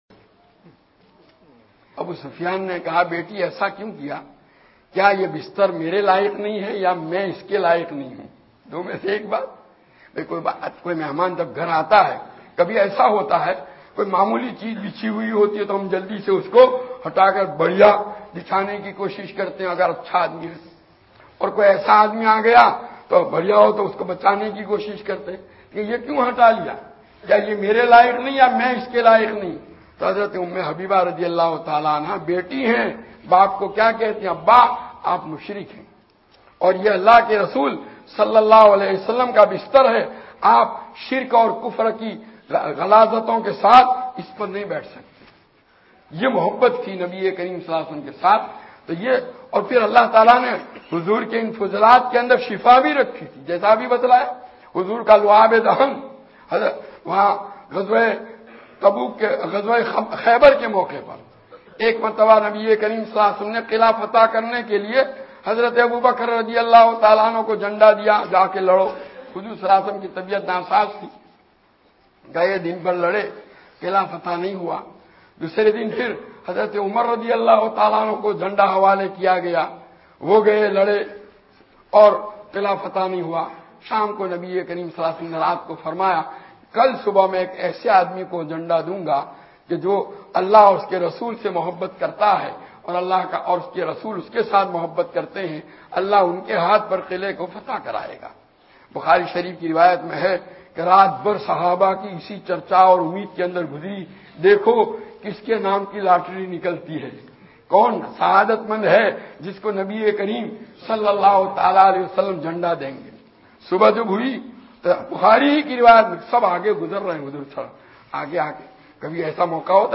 TOPIC PARTS CHUDGAR MUSJID - SURAT DEC 2010 ONE TWO 1 Al Ameen Islamic Audio Library, Pietermaritzburg, KZN, South Africa.
URDU LECTURES BY